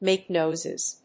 アクセントmàke nóises